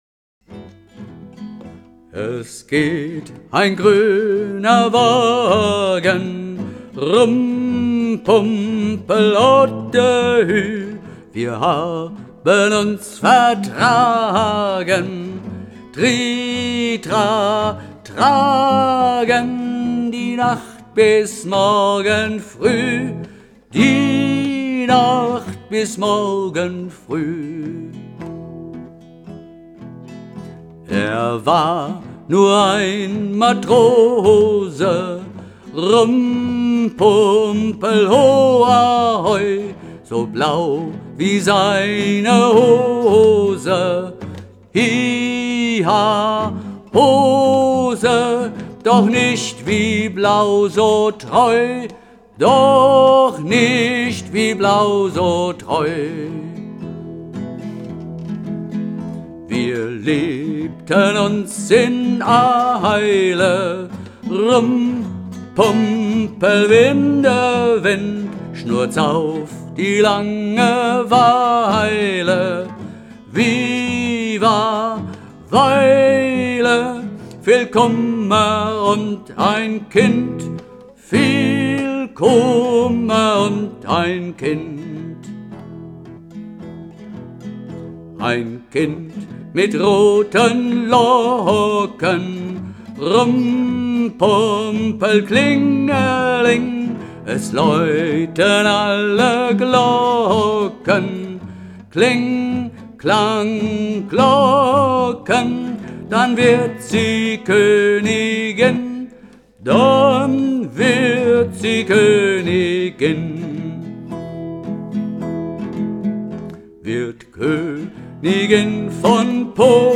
solo_der-gruene-wagen_voll.mp3